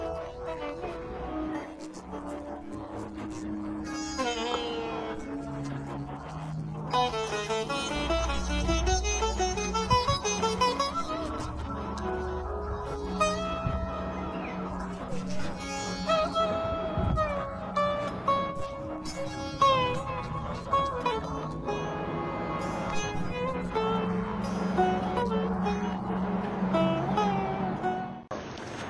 instrumental.wav